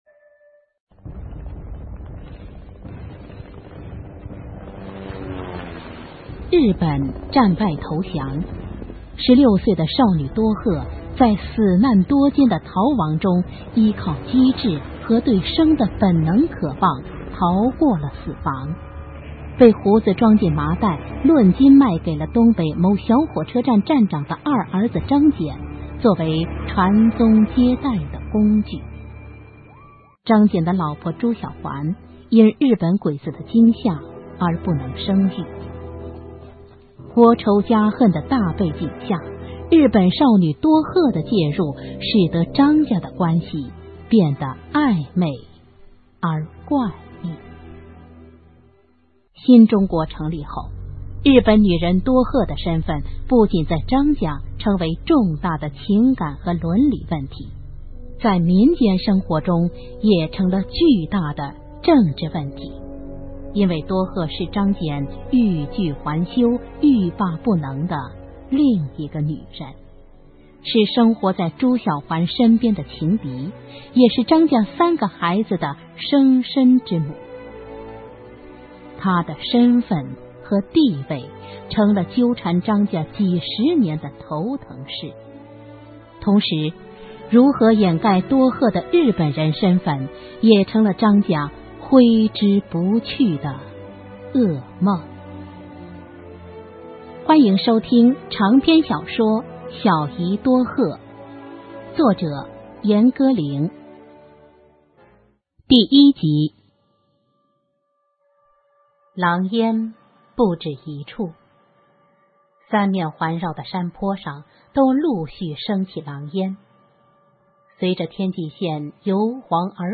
[2/3/2011]【有声文学】《小姨多鹤》[全48集]（原著 严歌苓 播讲者 佚名）[32K MP3][115网盘]